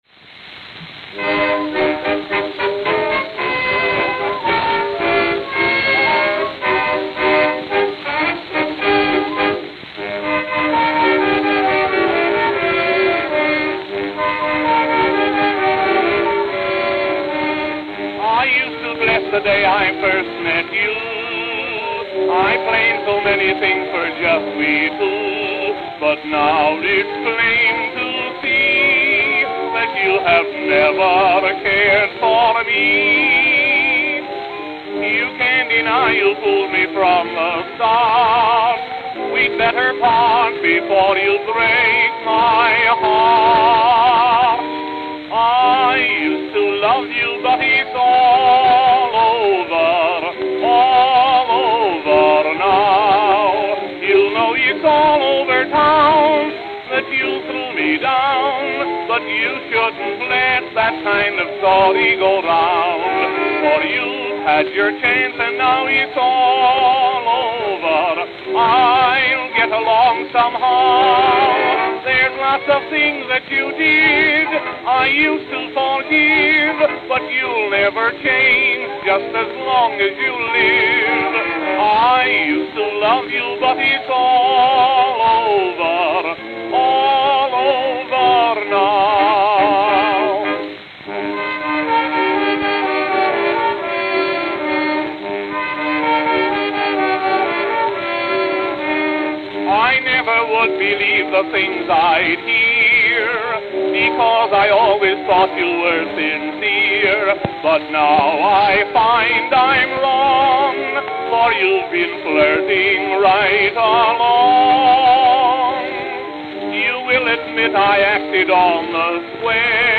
I even only use the left channel when transferring these.